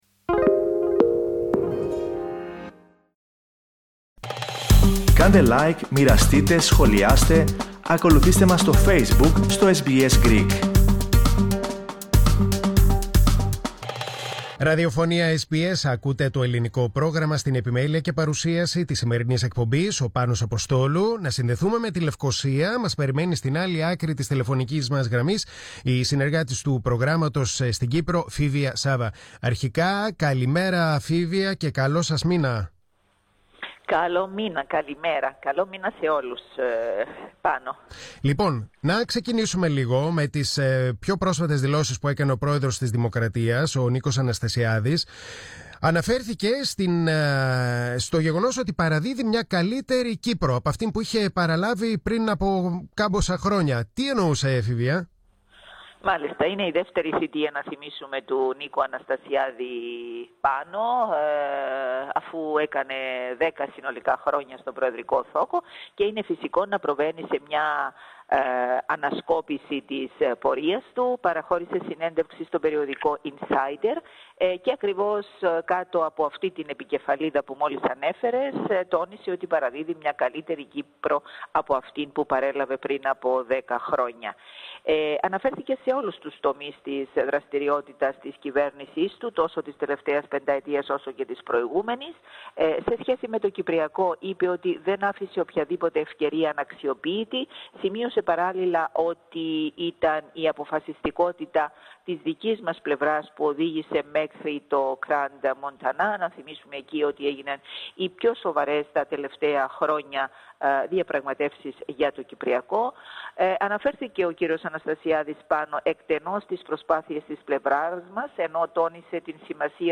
Ακούστε, ολόκληρη την ανταπόκριση από την Κύπρο.